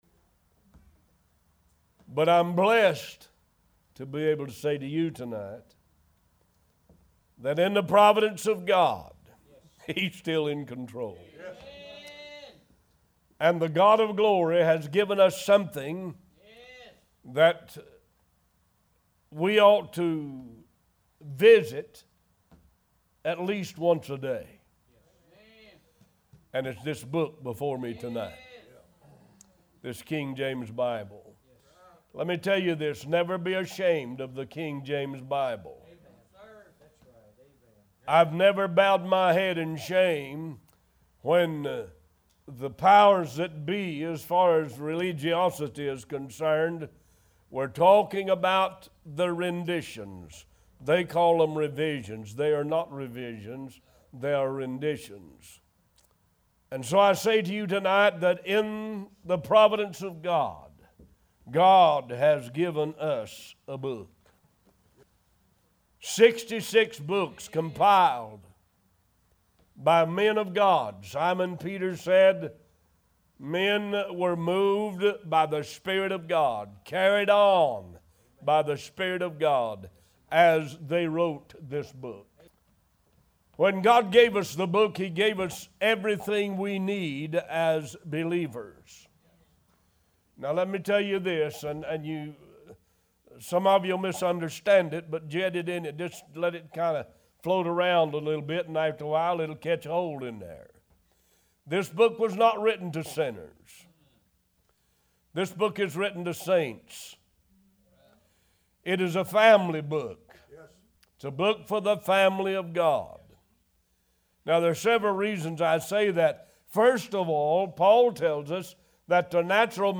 Talk Show Episode
New Sermons published every Sunday and Wednesday at 11:30 AM EST